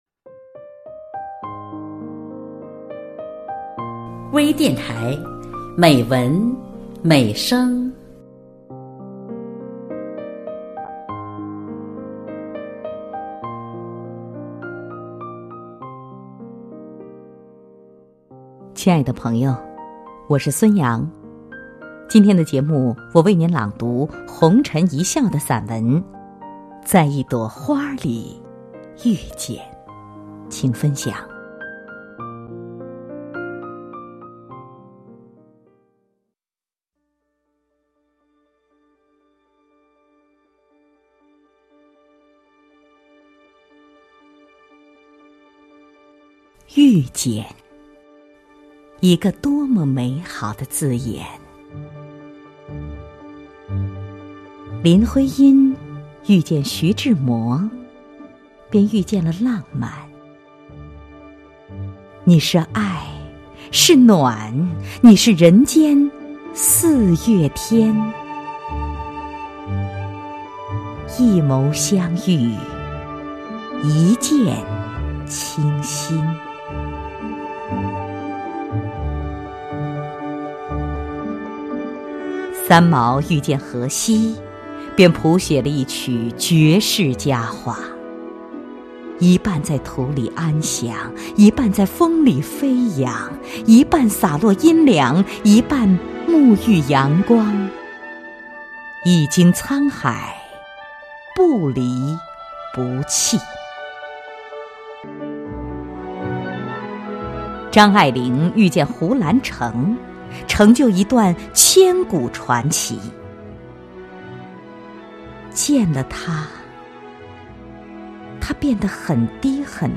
多彩美文  专业诵读